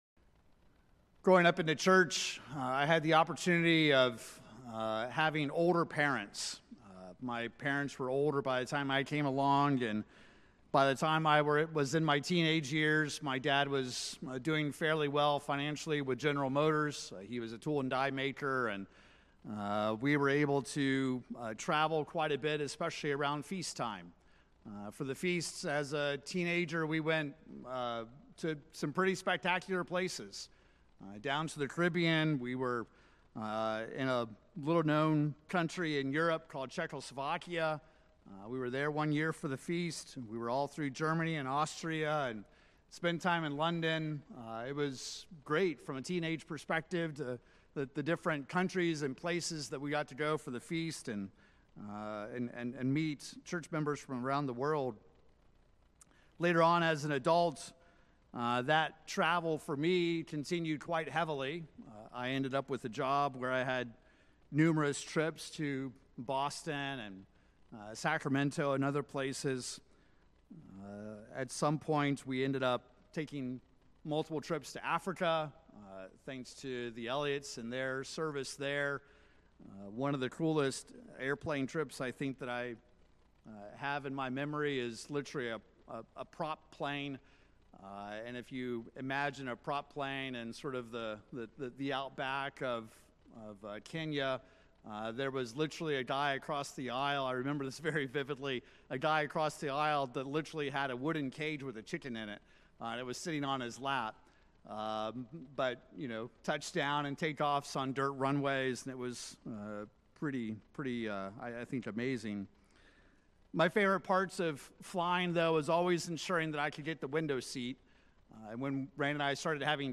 Sermons
Given in Oklahoma City, OK Tulsa, OK